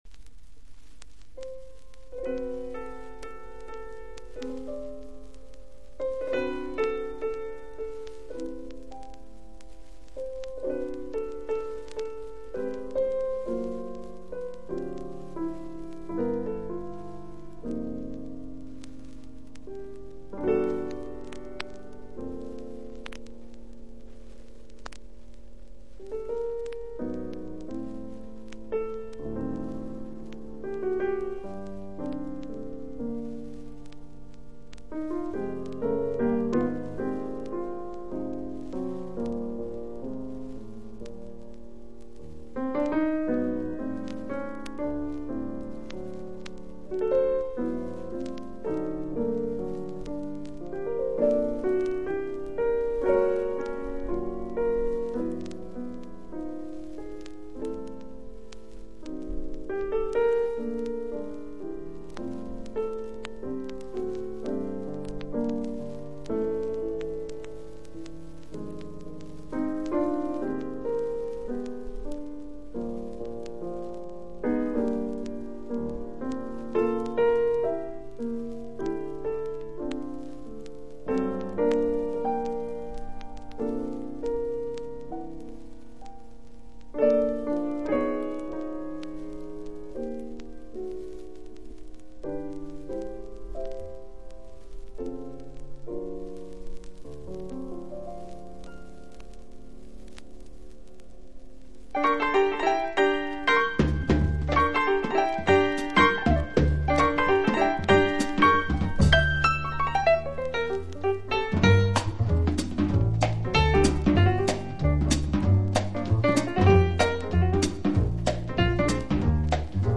(MONO針で聴くとほとんどノイズでません)※曲…